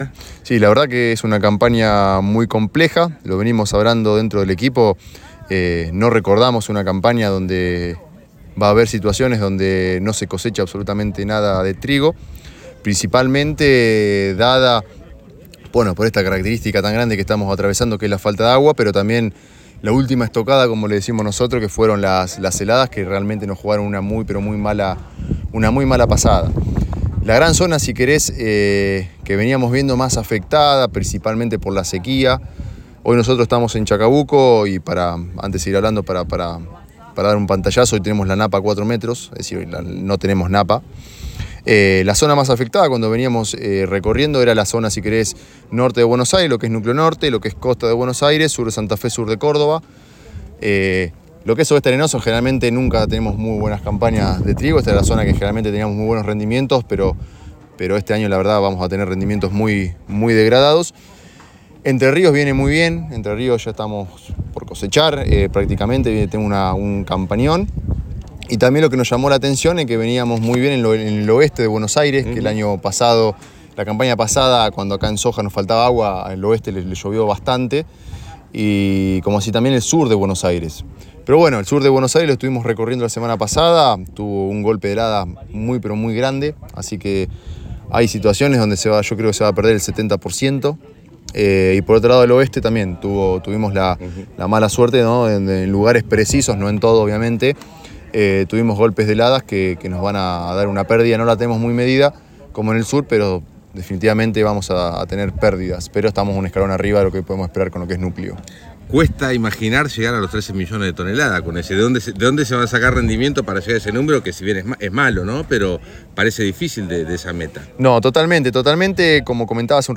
El habitual intercambio entre disertantes y público este año en el establecimiento Don Florencio de Chacabuco, Buenos Aires, se emparentó más con una catarsis colectiva donde cada quien expuso las penurias de la campaña triguera, en especial en la castigada zona núcleo.